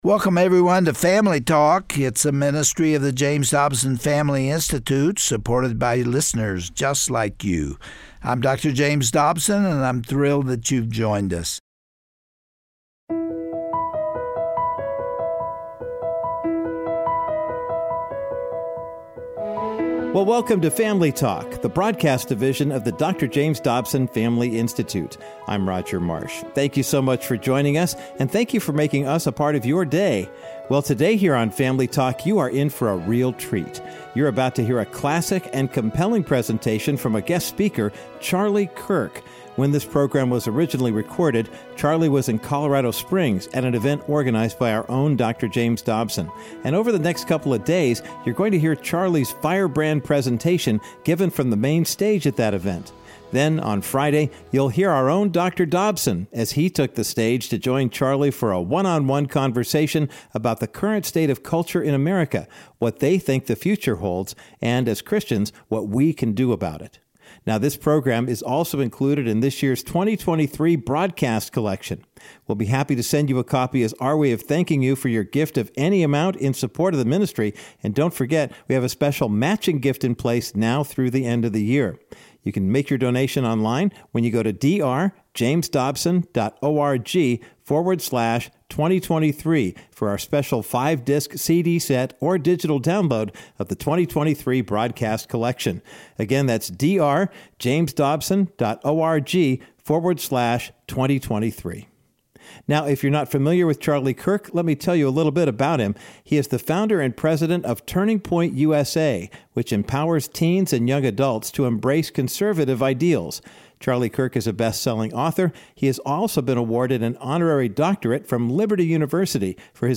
On today’s edition of Family Talk, Charlie Kirk, the founder and president of Turning Point USA, states that there are many in the Church who are neither strong nor courageous in the face of society’s changing tide. He points to various issues in our nation and asserts that if the American Church does not rise up, our country will soon fall apart.